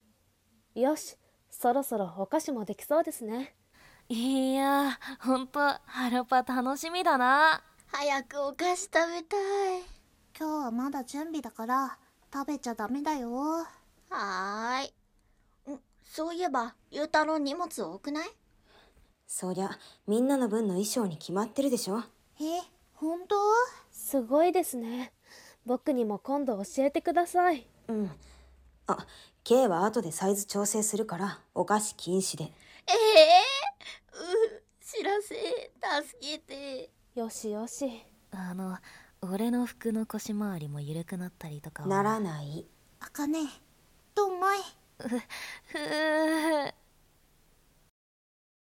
声劇